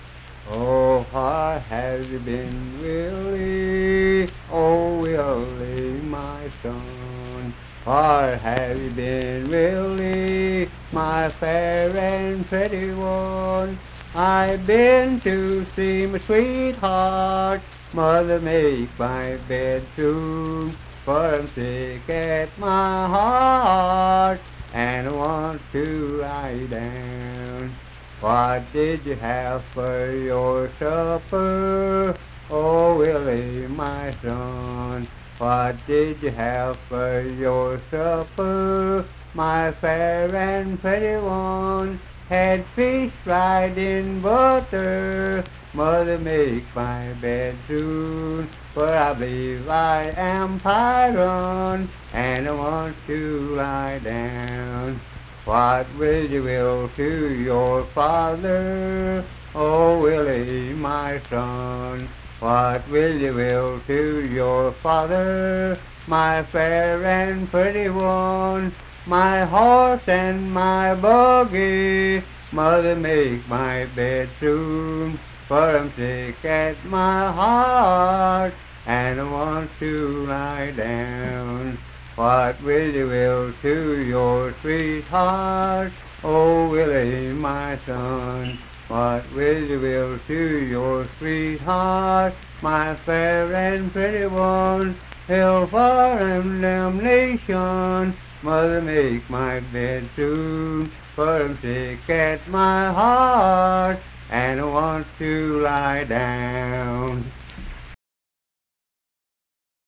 Unaccompanied vocal and banjo music
Verse-refrain 4(8).
Voice (sung)